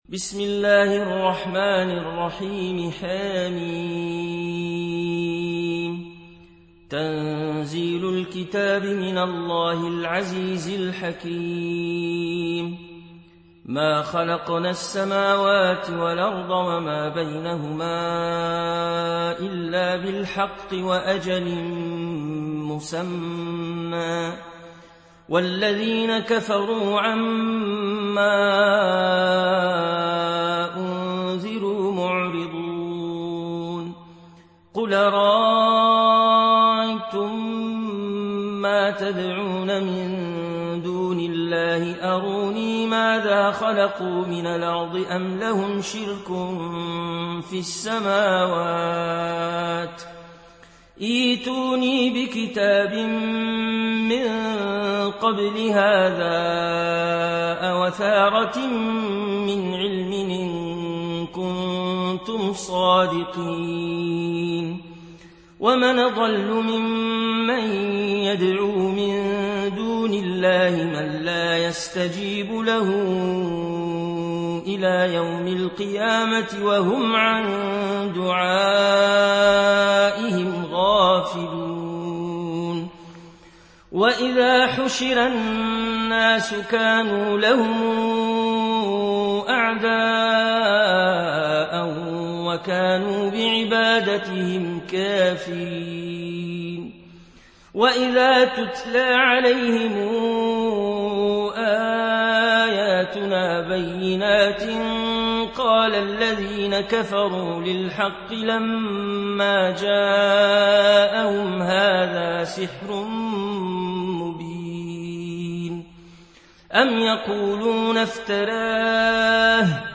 Riwayat Warch an Nafi